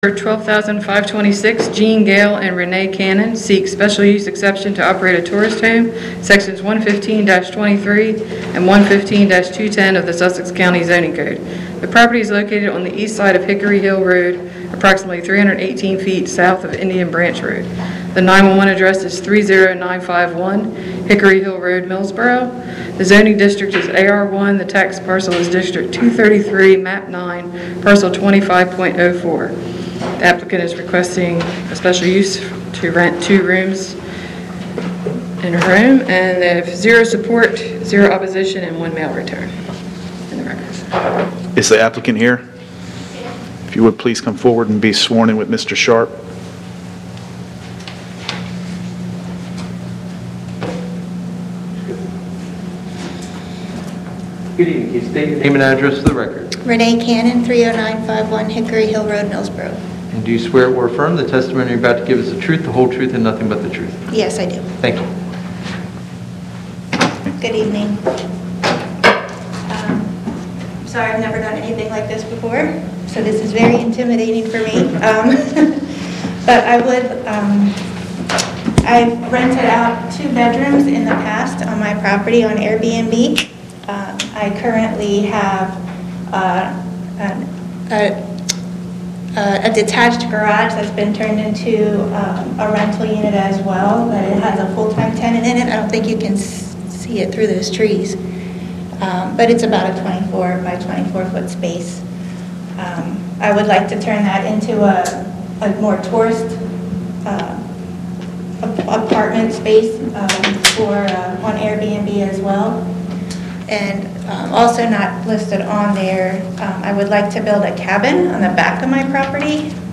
Meeting location: Council Chambers, Sussex County Administrative Office Building, 2 The Circle, Georgetown
Meeting type: Board of Adjustment